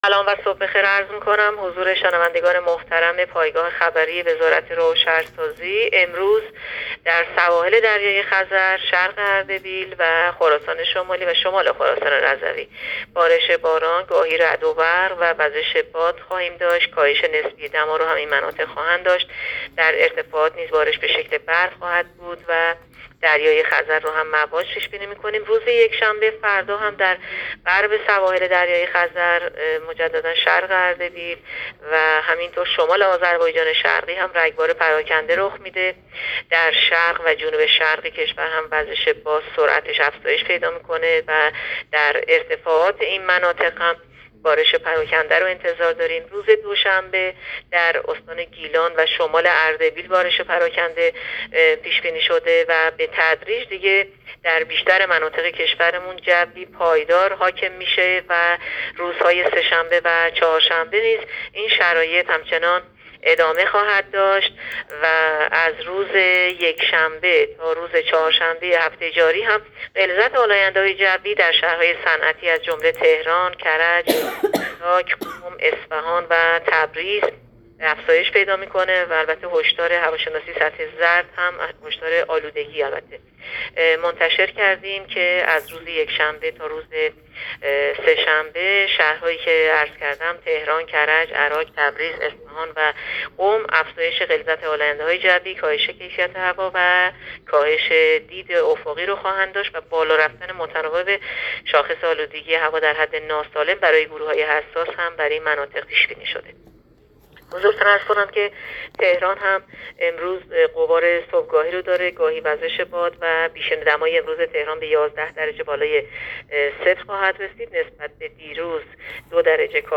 گزارش رادیو اینترنتی پایگاه‌ خبری از آخرین وضعیت آب‌وهوای ۱۰ آذر؛